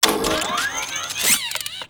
droneactivate.wav